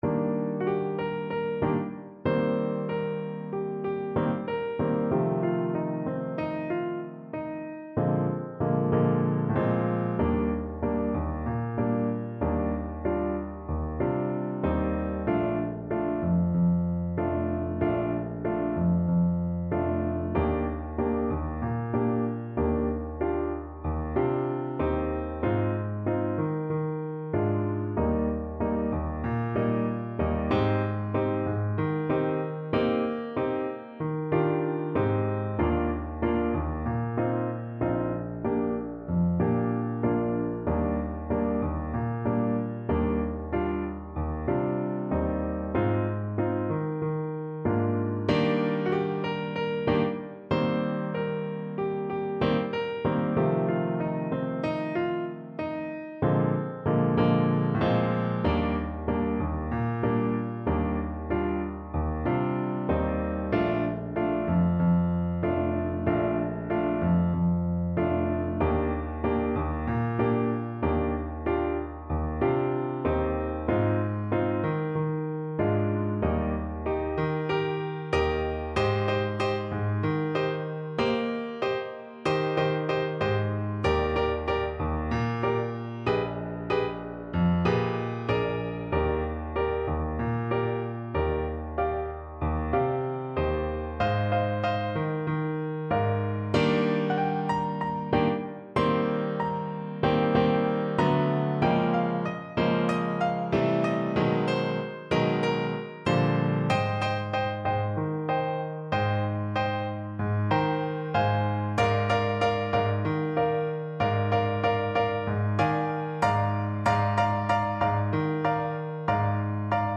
4/4 (View more 4/4 Music)
Allegro =c.126 (View more music marked Allegro)
Christmas (View more Christmas Trumpet Music)